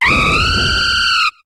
Cri de Flambusard dans Pokémon HOME.